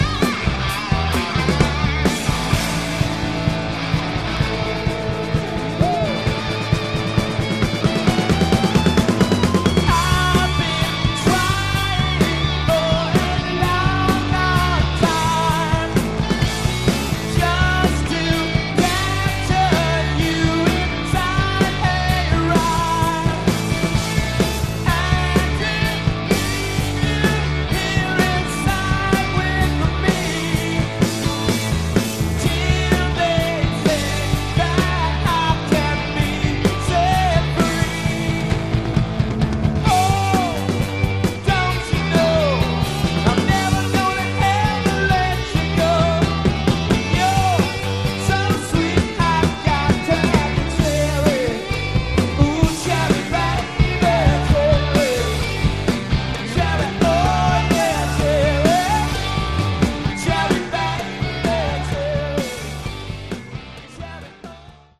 Category: Hard Rock
lead vocals
guitar
bass